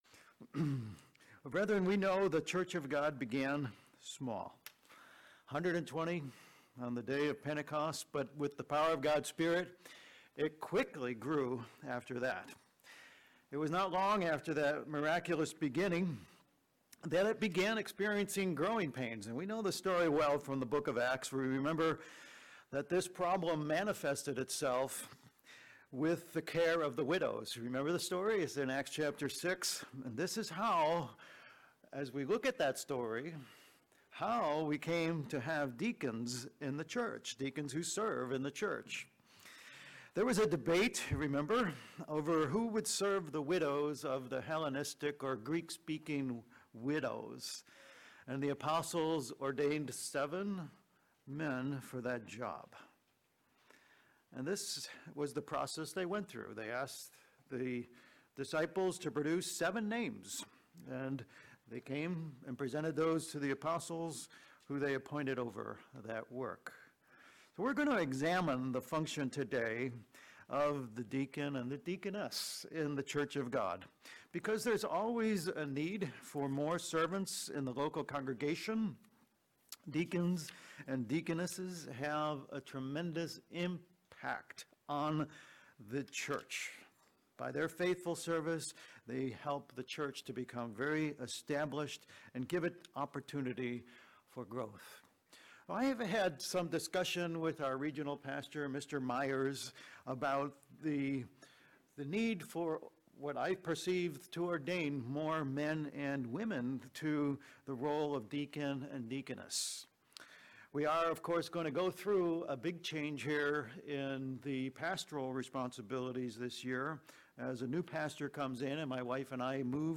Given in Bangor, ME Southern New Hampshire Saratoga Springs, NY Worcester, MA